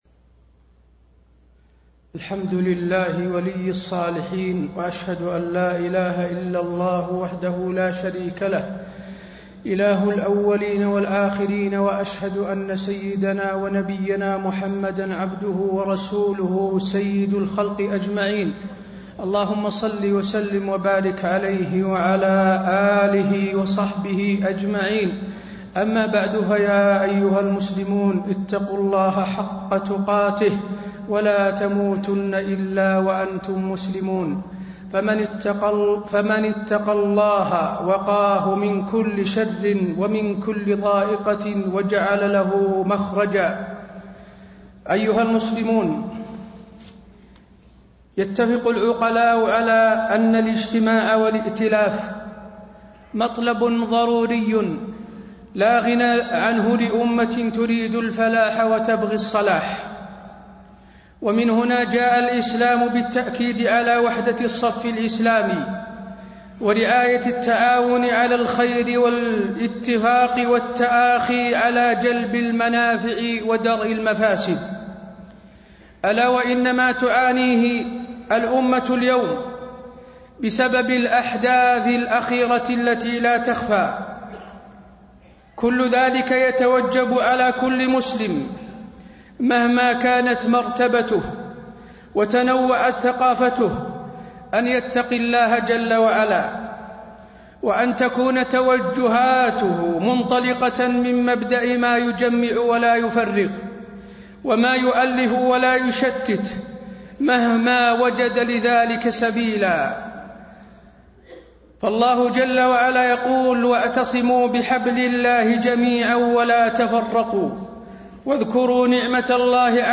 تاريخ النشر ١ صفر ١٤٣٤ هـ المكان: المسجد النبوي الشيخ: فضيلة الشيخ د. حسين بن عبدالعزيز آل الشيخ فضيلة الشيخ د. حسين بن عبدالعزيز آل الشيخ رسالة إلى عقلاء الأمة